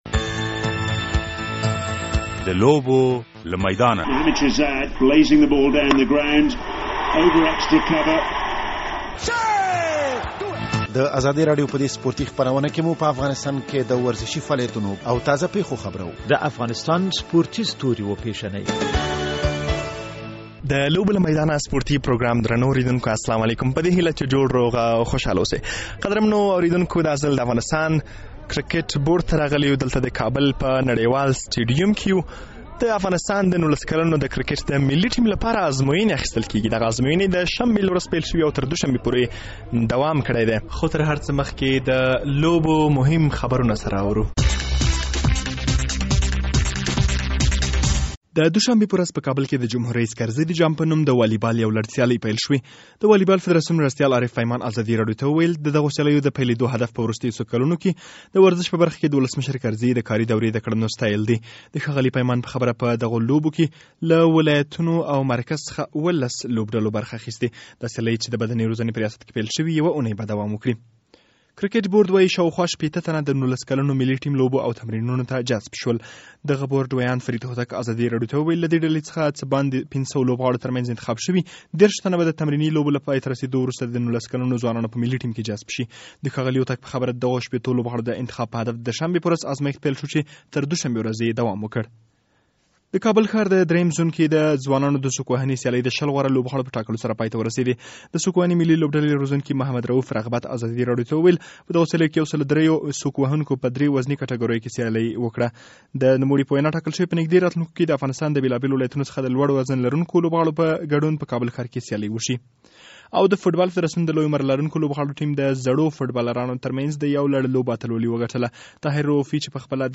درنو اوریدونکو سلام د لوبو له میدانه په اوسني پروګرام کې د افغانستان د کرکټ د نولس کلنو ملي ټیم ته د انتخابي ازمایښتونو په اړه بشپړ راپور اوریدلئ شئ.